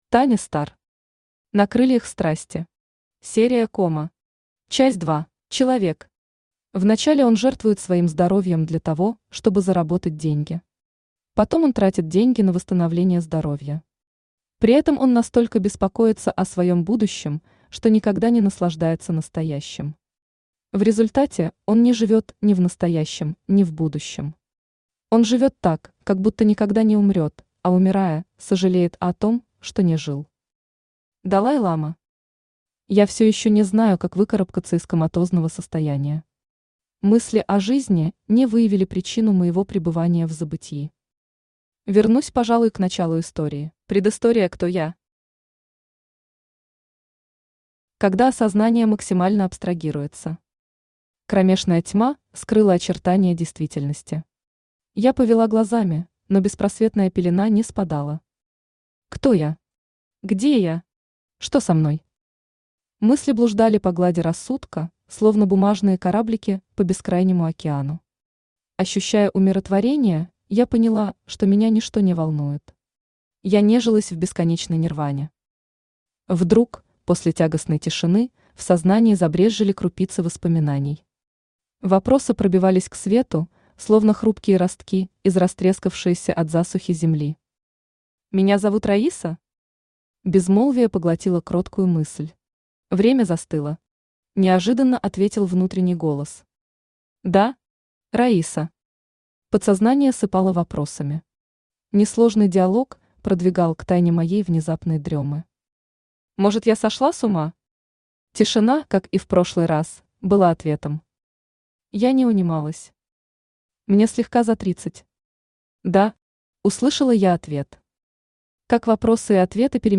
Аудиокнига На крыльях страсти. Серия Кома. Часть 2 | Библиотека аудиокниг
Читает аудиокнигу Авточтец ЛитРес